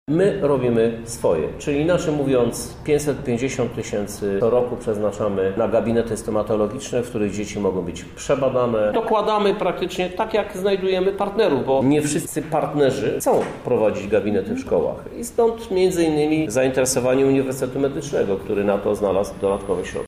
tłumaczy Prezydent miasta Lublin Krzysztof Żuk.